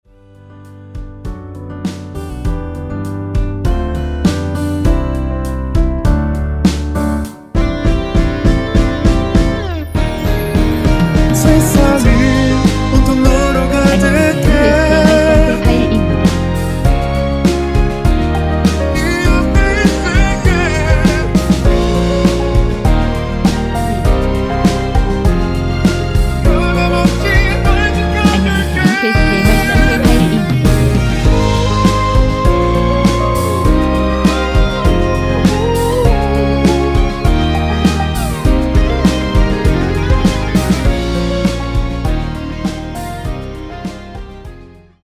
이곡의 코러스는 미리듣기 부분이 전부 입니다.
원키에서(-2)내린 코러스 포함된 MR입니다.
앞부분30초, 뒷부분30초씩 편집해서 올려 드리고 있습니다.